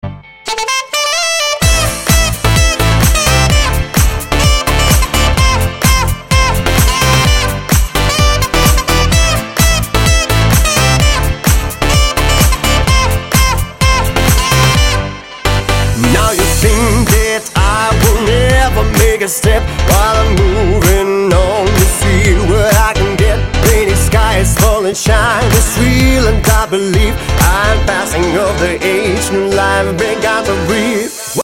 • Качество: 128, Stereo
позитивные
веселые